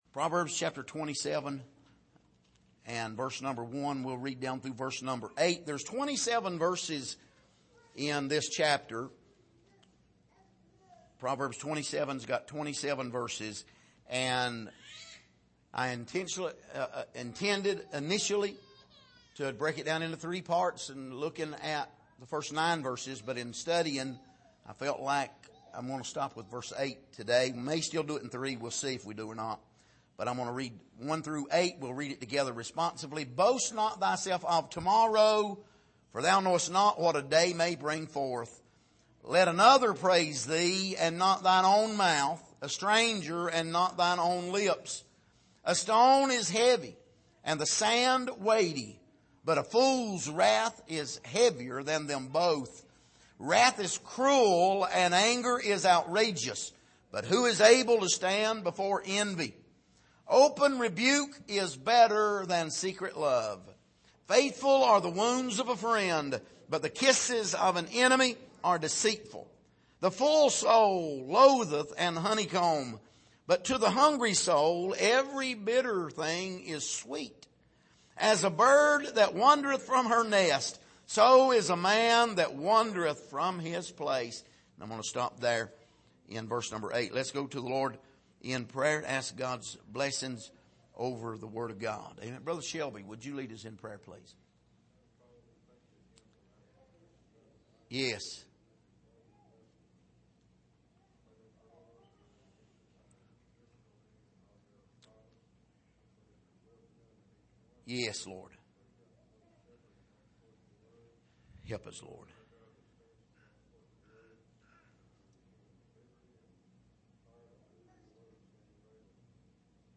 Passage: Proverbs 27:1-8 Service: Sunday Evening